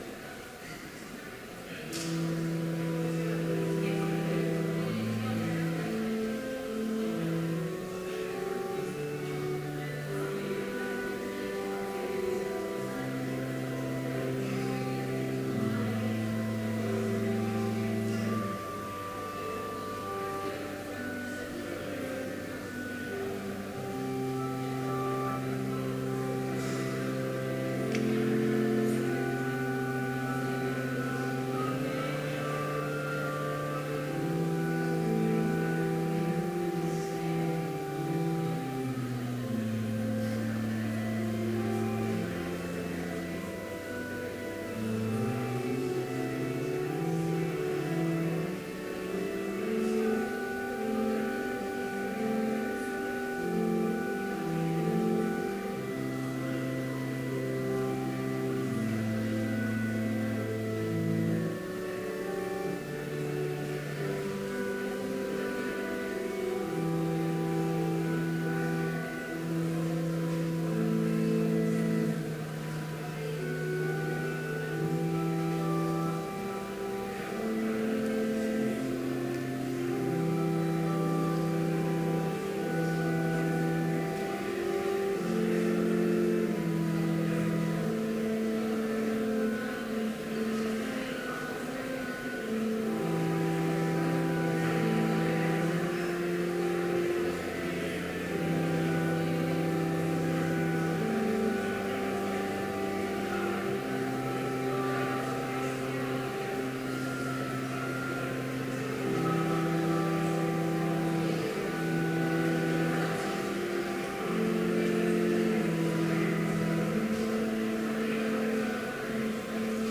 Complete service audio for Chapel - September 17, 2014